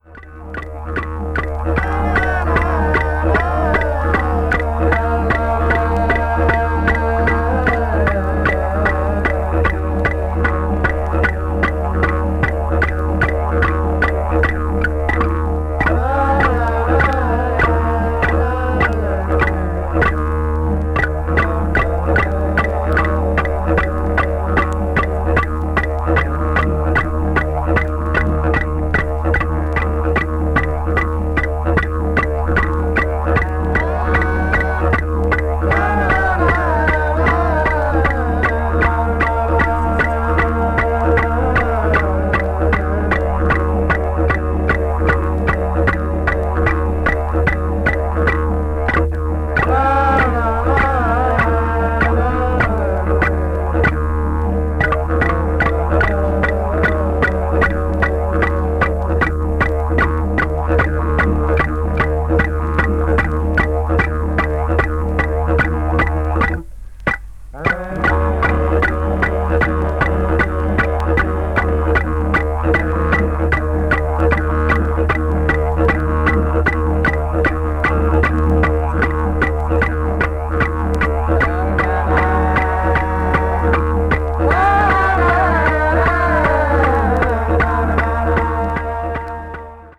Arnhem Land Popular Classics : Aboriginal Dance Songs with Didjeridu Accompaniment
aborigini   australia   didjeridu   ethnic music   folk   traditional   world music